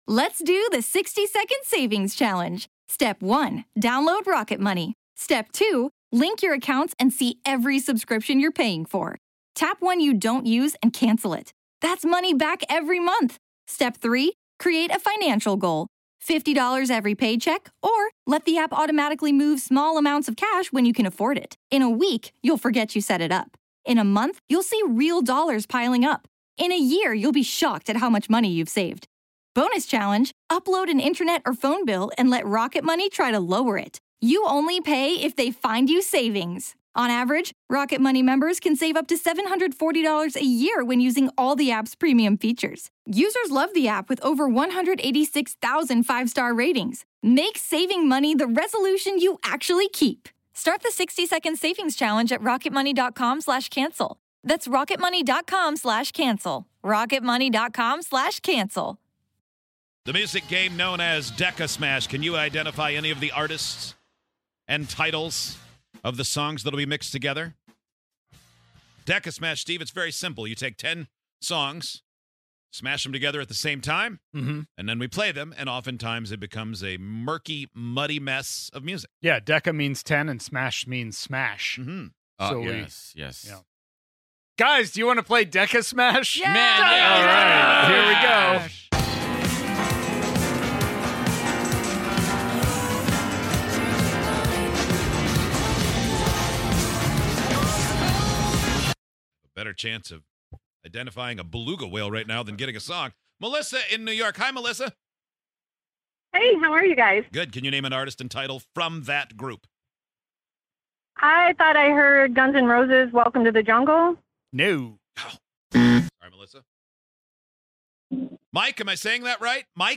put together a list of ten songs, smashed them all together, and played them at the same time. Can you guess any of the 10 songs from DECASMASH?